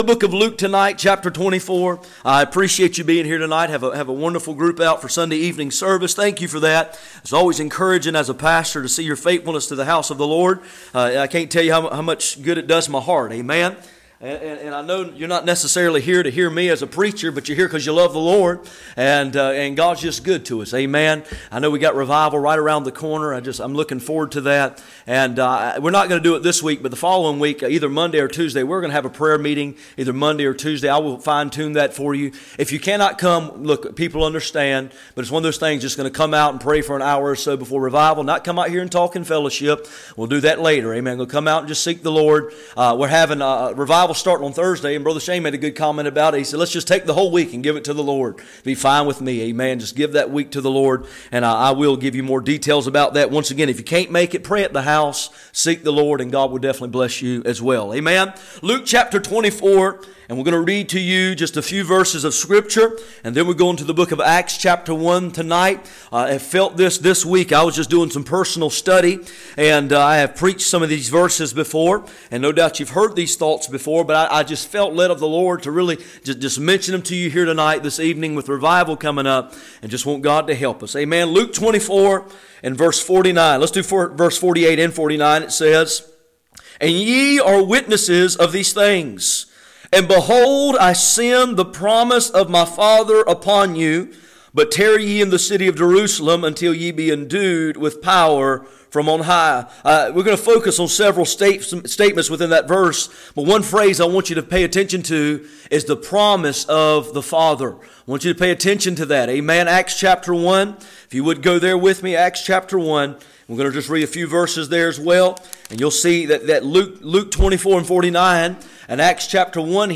None Passage: Luke 24:48-49, Acts 1:4-8 Service Type: Sunday Evening %todo_render% « Making sense of suffering.